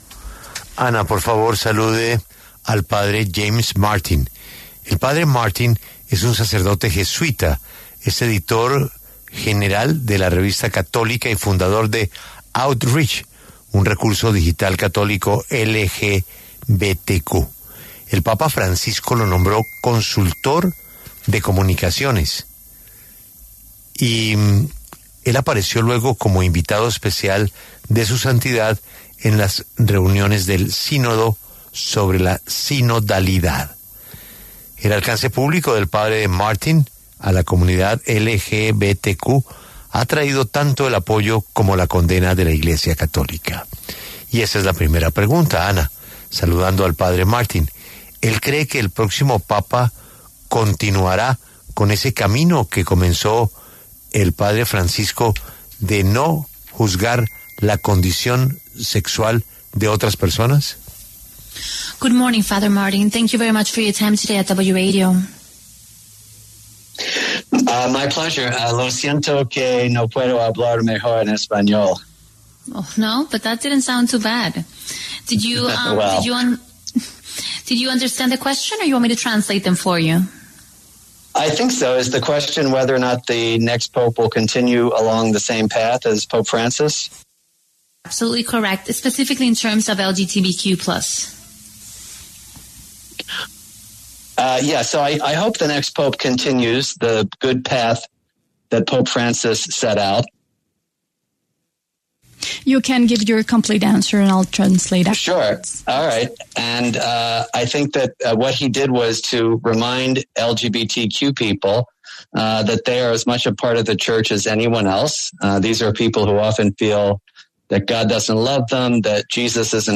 El padre James Martin, sacerdote jesuita cercano al papa Francisco y destacado por trabajar de la mano con comunidades LGBTI, conversó con La W sobre la llegada del nuevo papa.